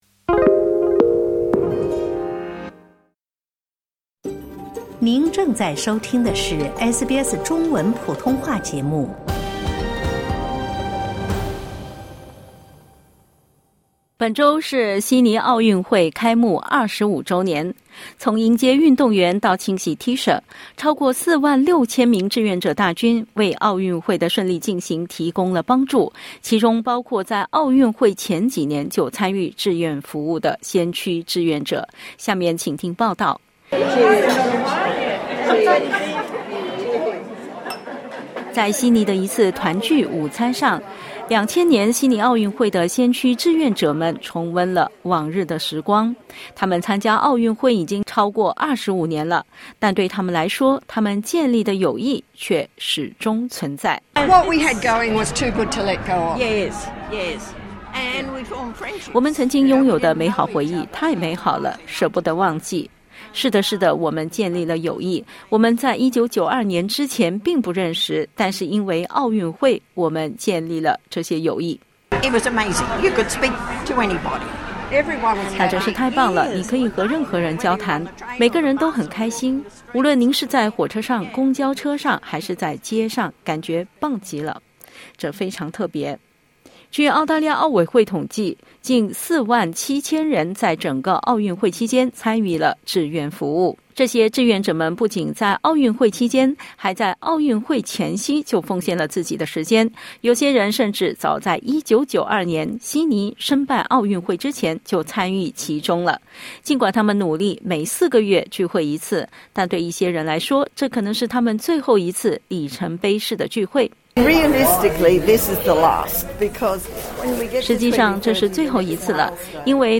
本周，当年悉尼奥运会的志愿者们开展了一些列纪念活动。（点击音频收听详细报道）